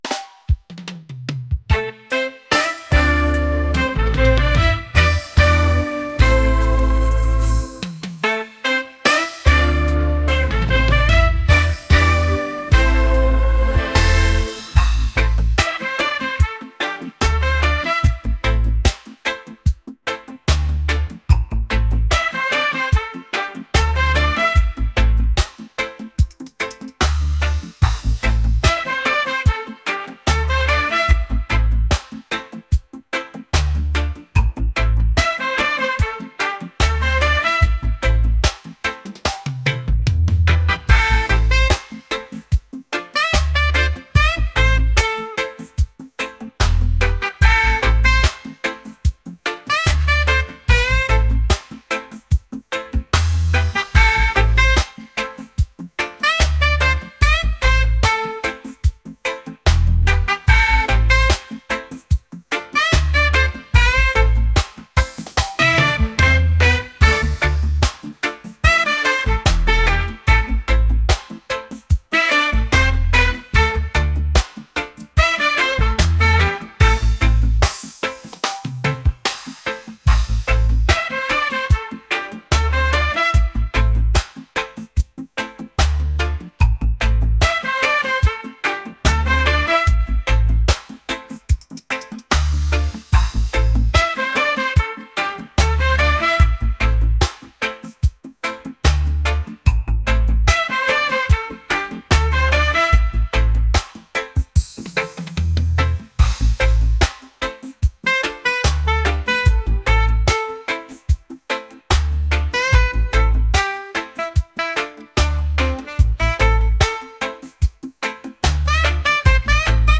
reggae | lofi & chill beats | folk